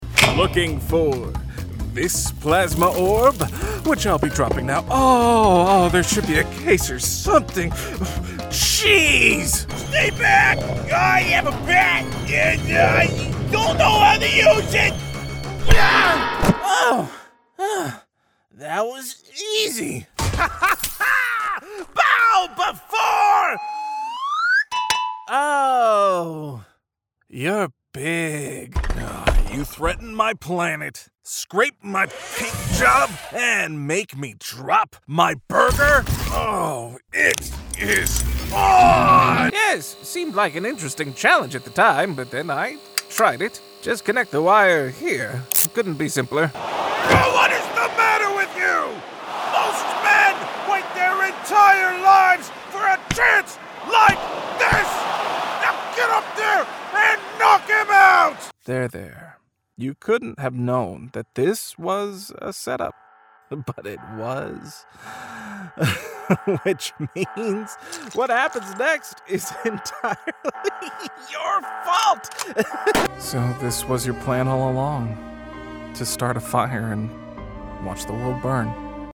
Character Demo
Young Adult
Middle Aged
He has also set up a broadcast quality home studio, and is proficient in audio editing.